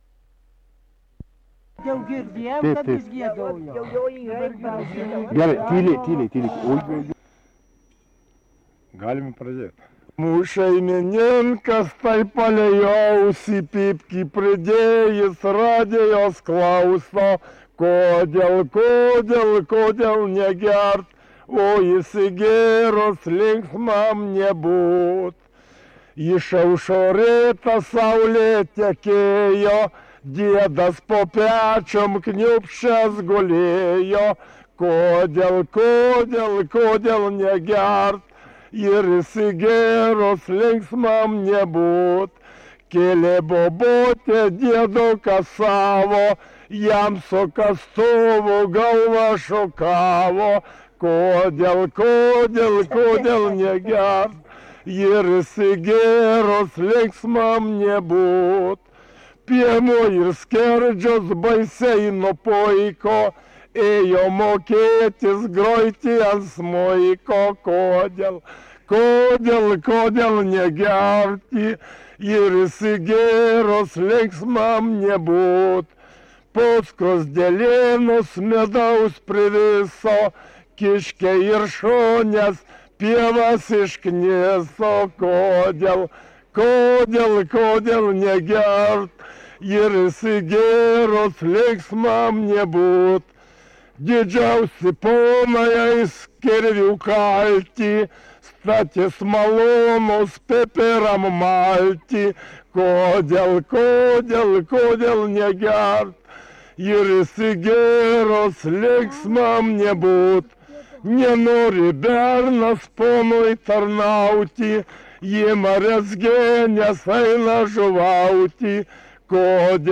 daina
Subartonys
vokalinis